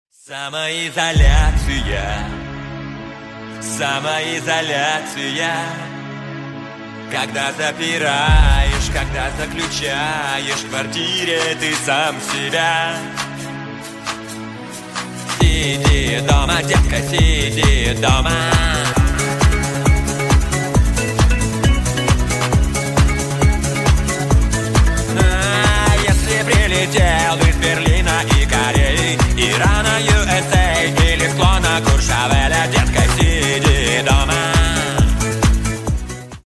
Кавер И Пародийные Рингтоны